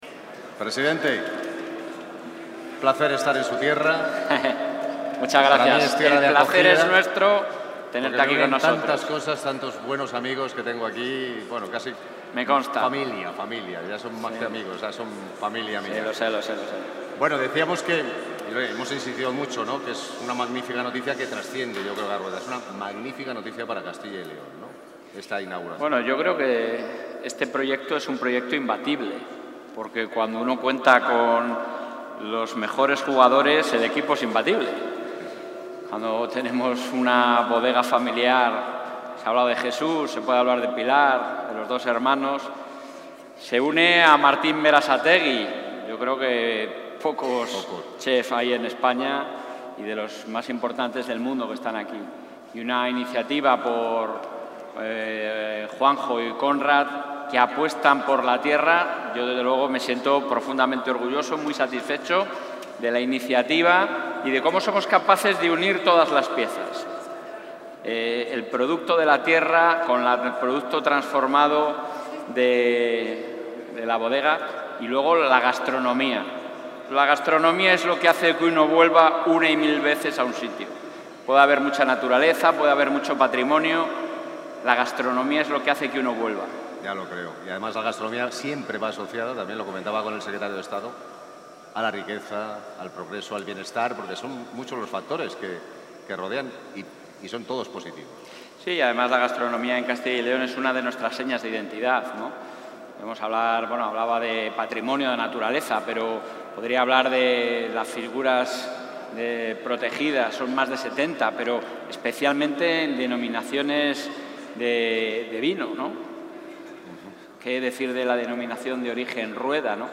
Intervención presidente.
En la inauguración de la gastrobodega 'El Hilo de Ariadna by Martín Berasategui', en el municipio vallisoletano de Rueda, el presidente autonómico ha destacado el potencial de la industria agroalimentaria y vitivinícola en la Comunidad, generadora de riqueza y empleo.